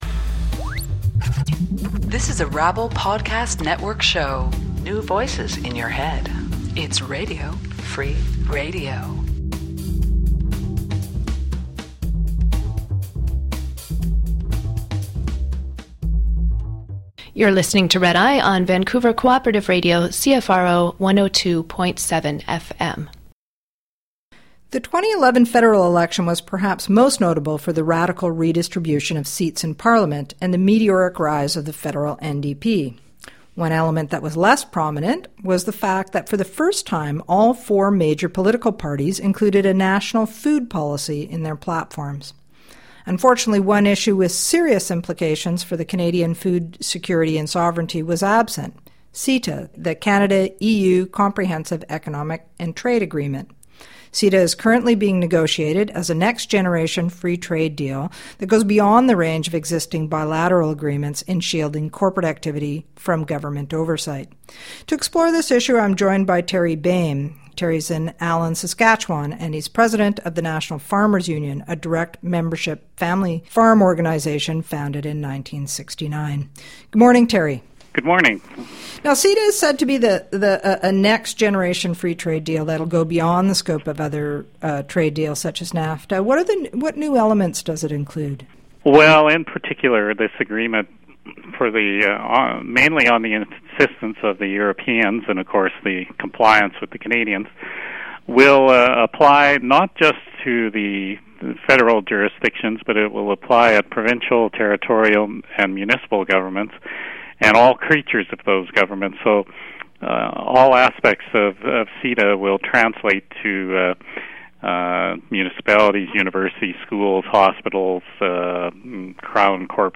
Vancouver Cooperative Radio Genre: Interview Year